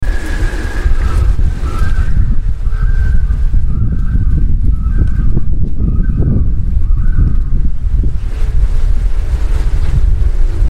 Download Loon sound effect for free.
Loon